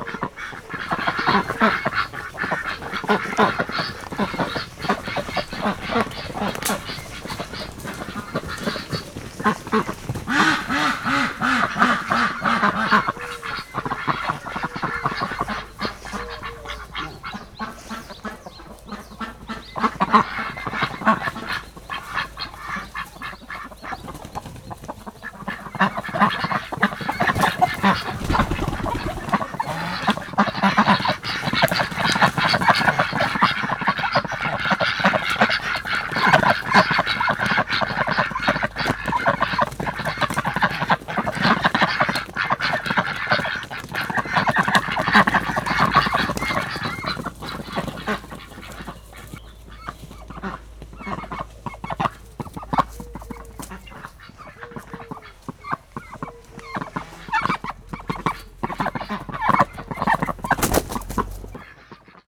Directory Listing of //allathangok/parasztudvar/hazikacsa/